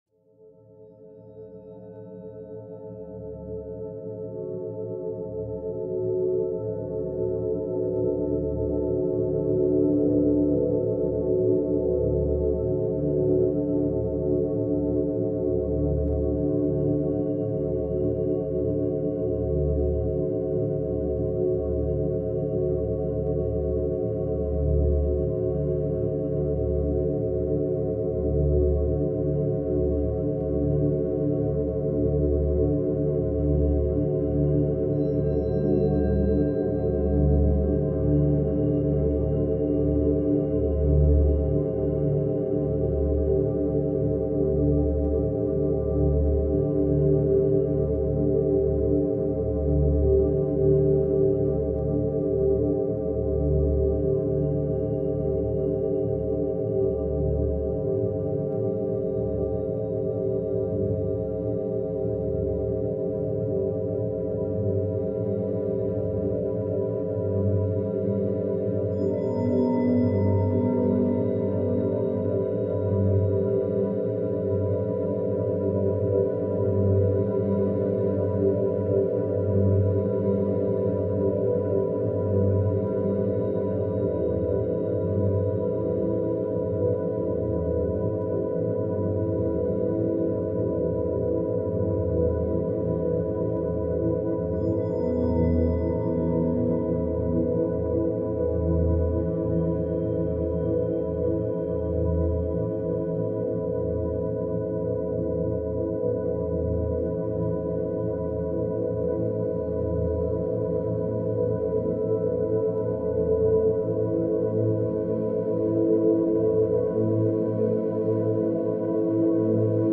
Background Sounds, Programming Soundscapes, Coding Beats